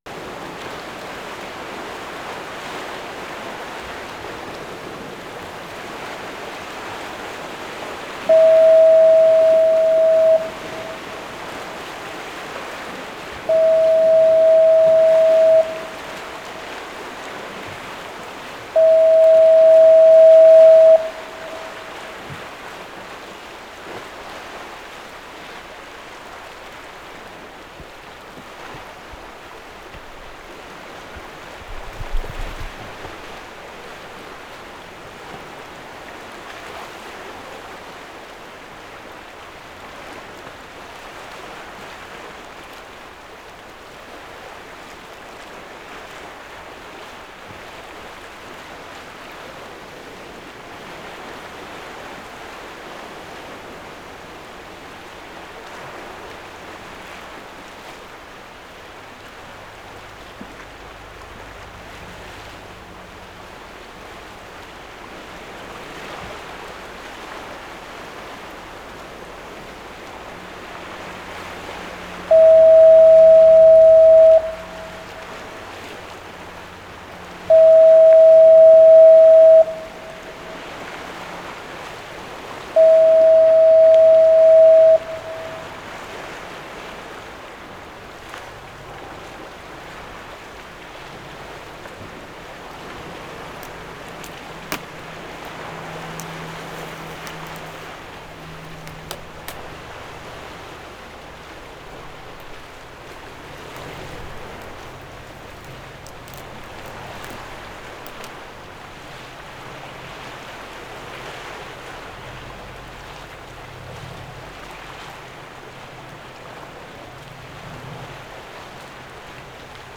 Pt. Atkinson electronic foghorn 4:10
9. Pt. Atkinson electronic foghorn, 3 blasts (0:10, 1:13, 2:17, 3:20) with wave wash.